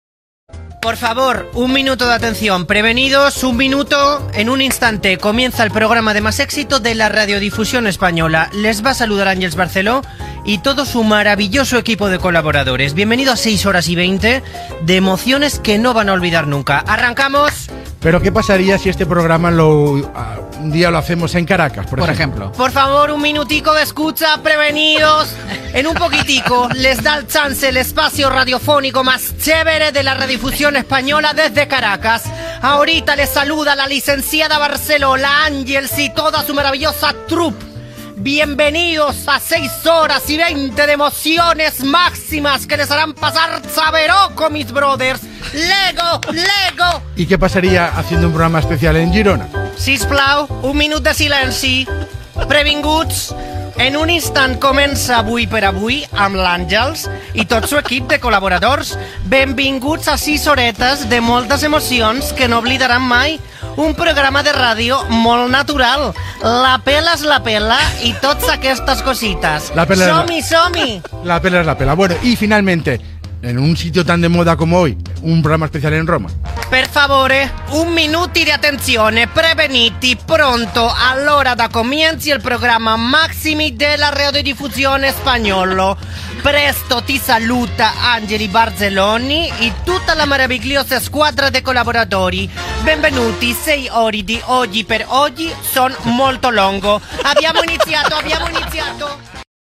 Presentació humorística multilingüe prèvia a l'inici del programa que es fa des de Roma
Info-entreteniment
Programa presentat per Àngels Barceló.